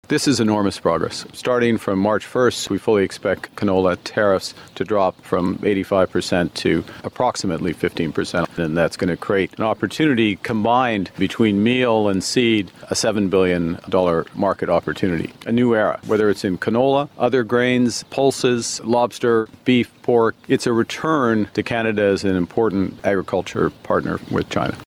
Audio with Canadian Prime Minister Mark Carney, Saskatchewan Premier Scott Moe, and U.S. President Donald Trump.